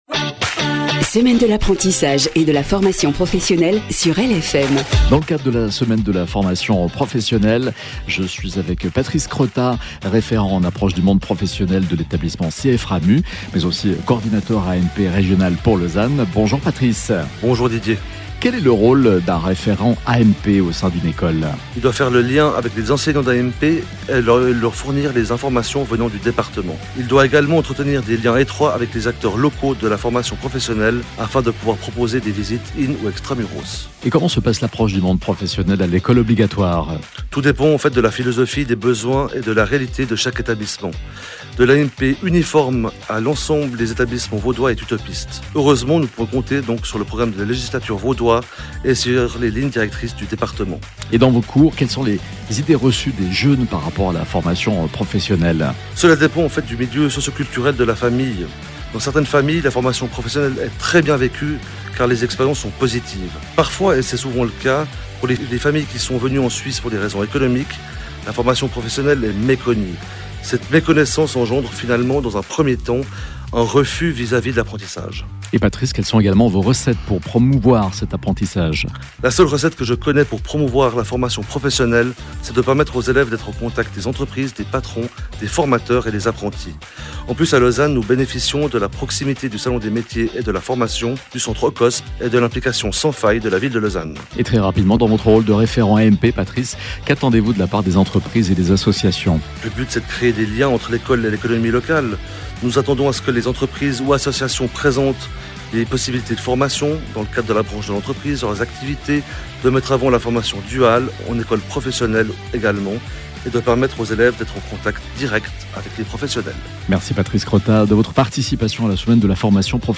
Dans le canton de Vaud, des invités parleront de leur expérience ou de leur vision du choix d’un apprentissage, chaque jour à 16h18 et à 18h48 sur LFM.
Programme des interviews